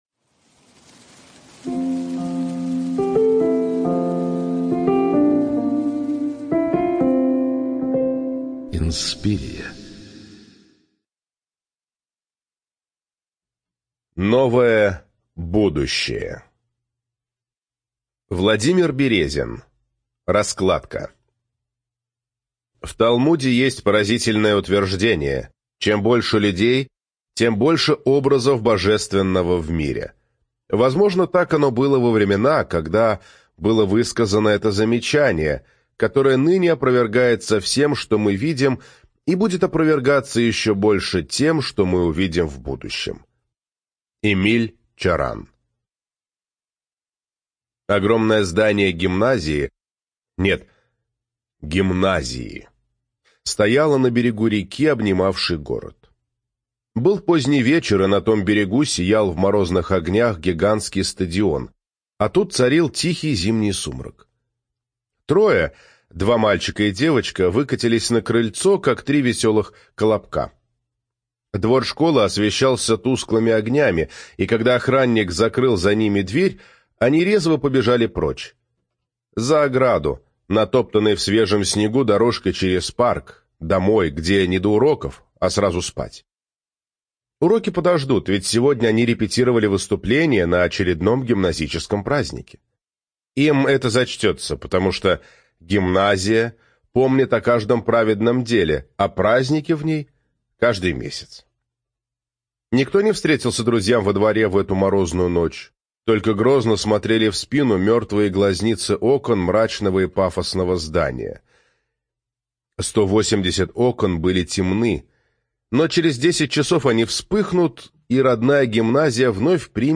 Студия звукозаписиInspiria